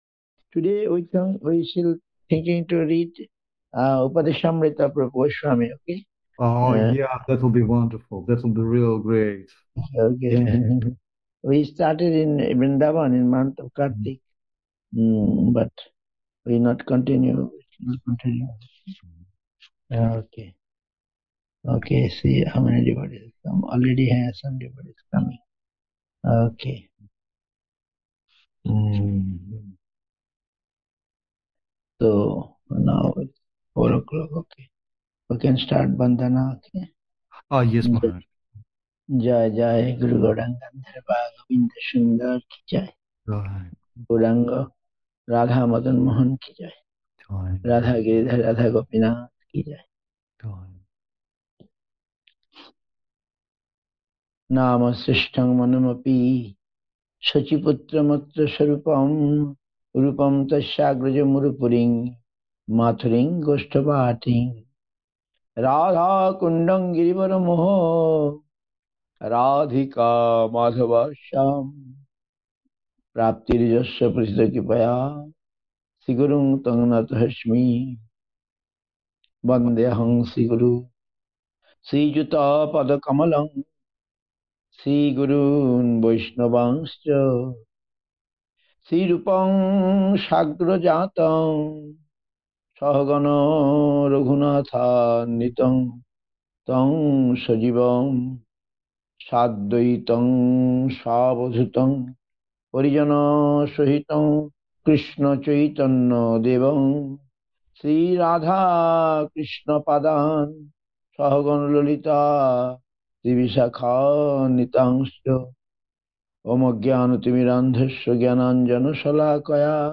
India, Nabadwip Dham, SREE CAITANYA SRIDHAR SEVA ASHRAM.